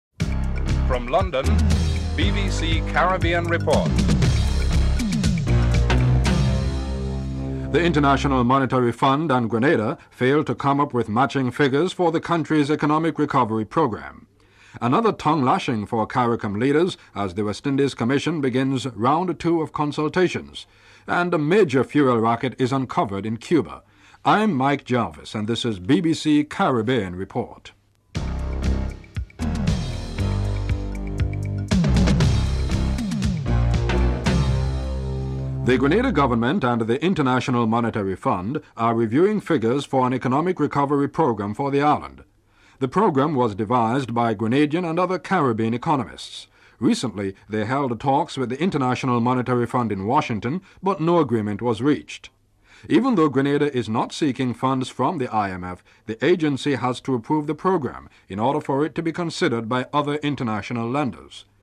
1. Headlines (00:00-00:34)
3. Interview with Grenada's Prime Minister Nicholas Brathwaite on what was hindering the IMF approval (01:08-03:42)